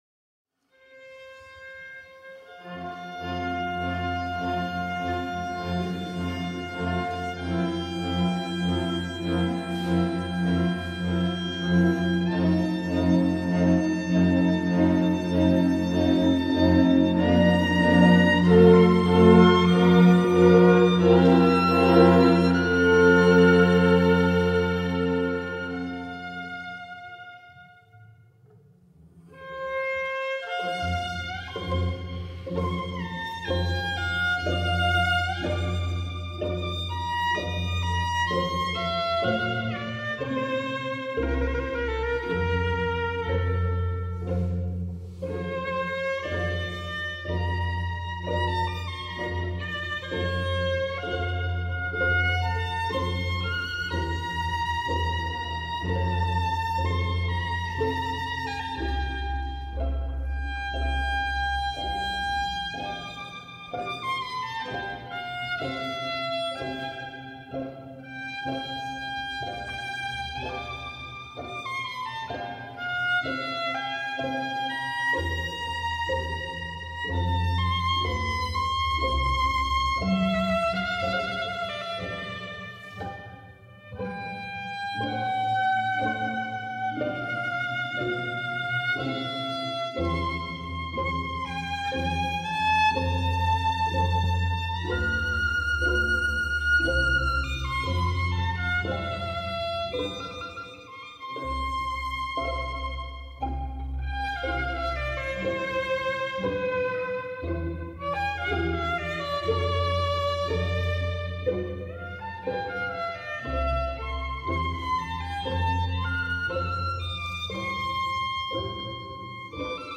Adagio du concerto n1 en do majeur pour violon et orchestre